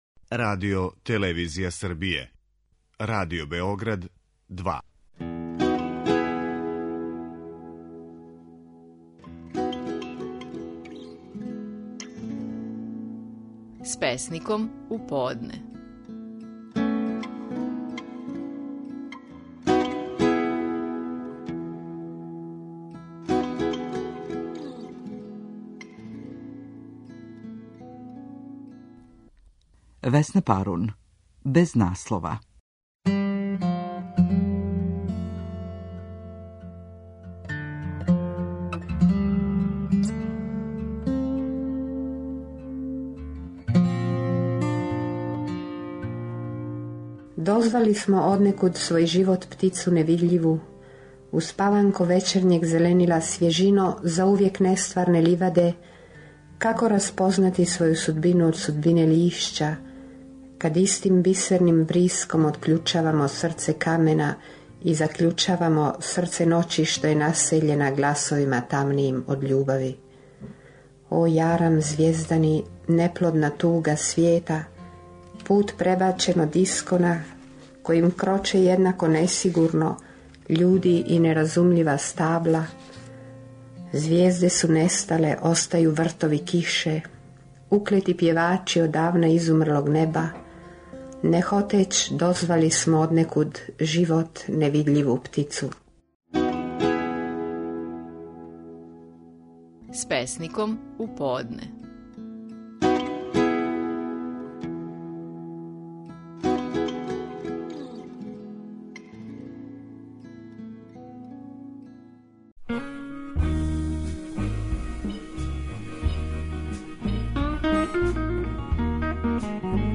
Наши најпознатији песници говоре своје стихове
"Звук судбине, звук прогонства" – назив је песме коју говори песник Стеван Тонтић.